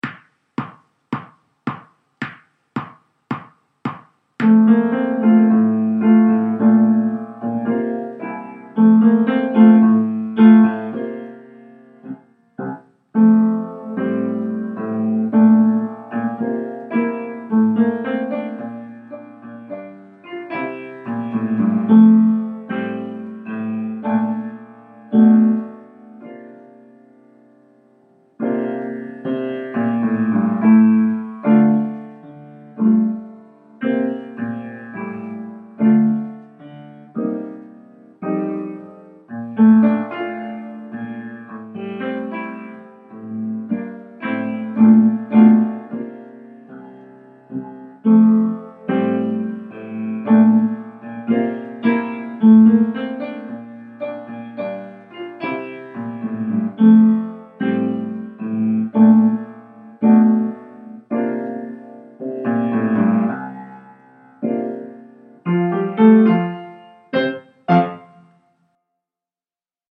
It couldn't be better piano